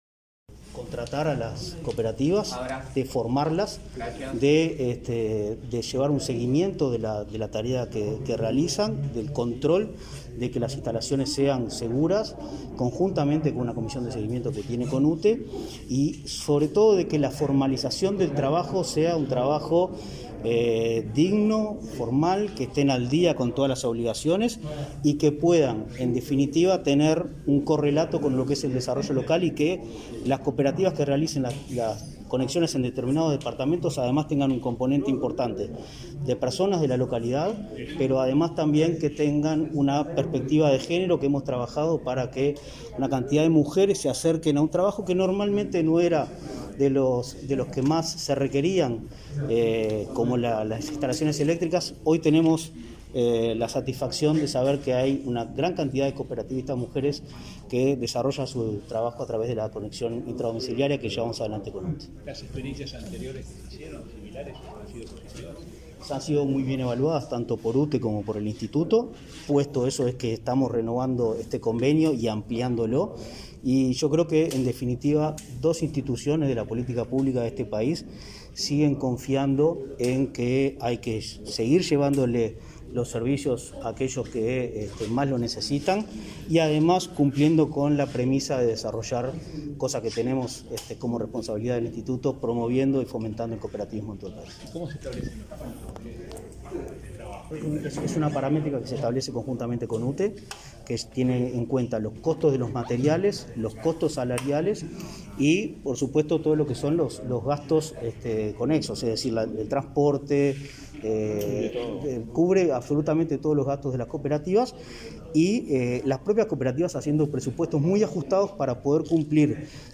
Declaraciones a la prensa del presidente del Instituto Nacional del Cooperativismo